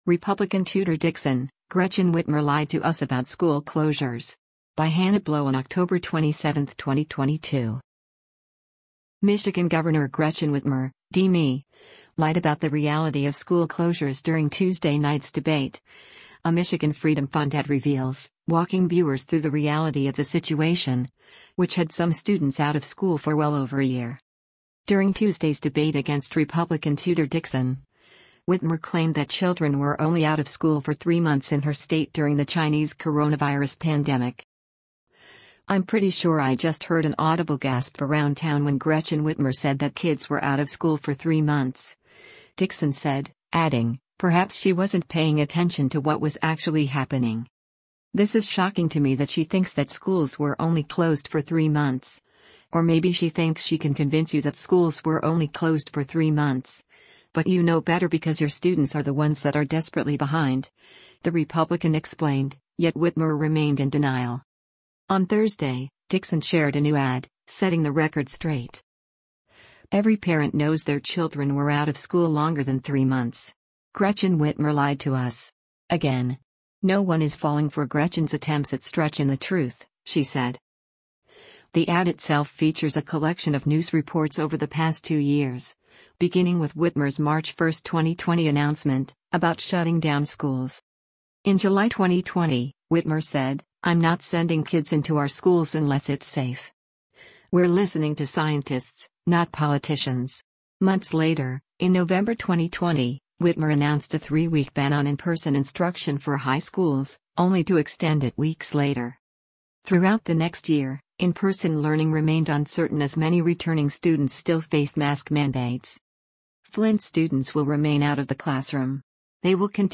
The ad itself features a collection of news reports over the past two years, beginning with Whitmer’s March 1, 2020 announcement, about shutting down schools.